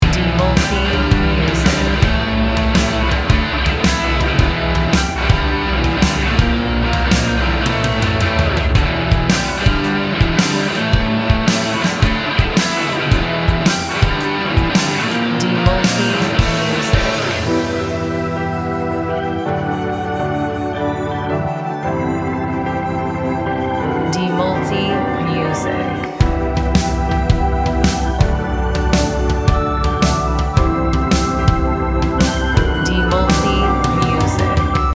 Gym Music Instrumental
Gitar yang menghantam bikin energi langsung terpicu.